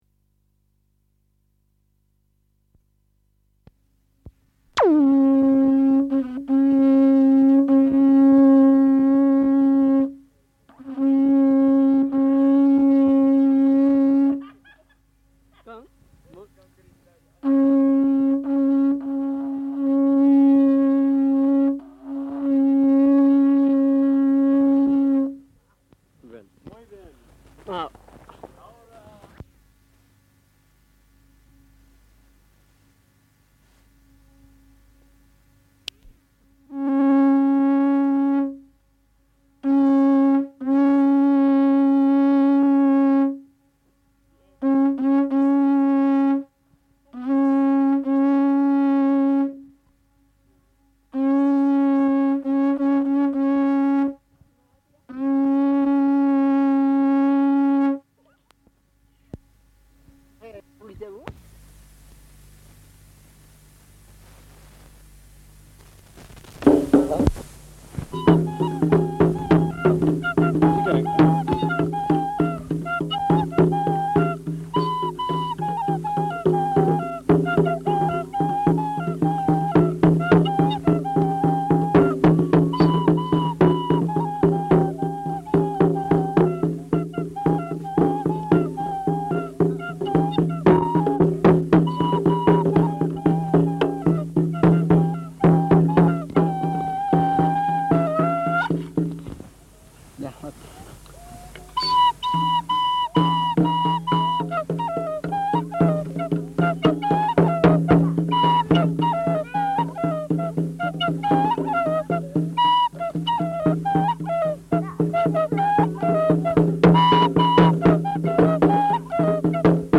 Excerpts from expedition tapes: recording of songs and the playing of musical instruments in the northern part of the Oriente of Ecuador made by members of the Oxford University Expedition to Ecuador in 1960.
From the sound collections of the Pitt Rivers Museum, University of Oxford, being one of a number of miscellaneous or individual ethnographic field recordings (rediscovered during a recent research project).